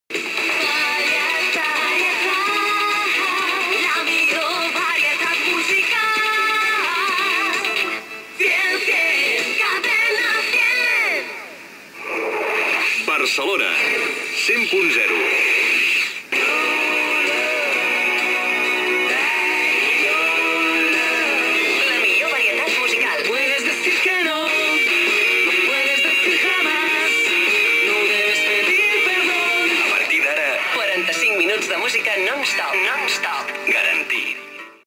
Indicatiu de la ràdio, freqüència, música "non stop"